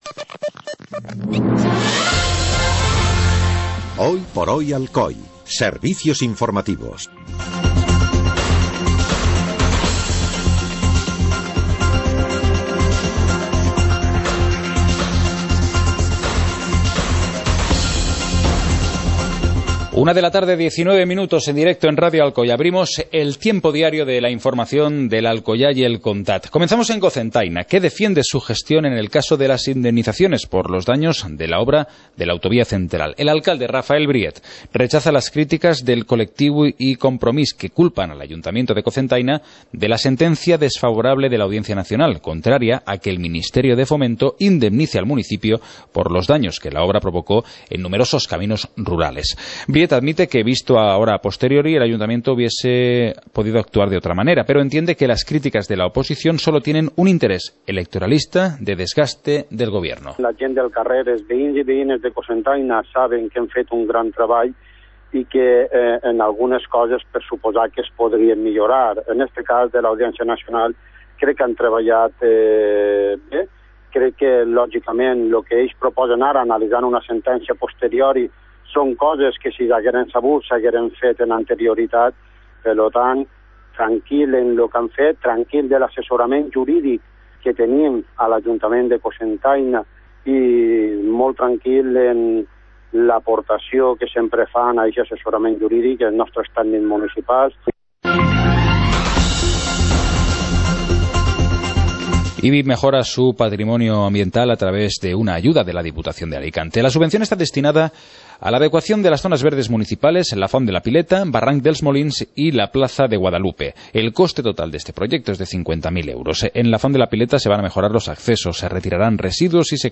Informativo comarcal - viernes, 09 de enero de 2015